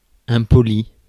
Ääntäminen
IPA : /ˈruːd/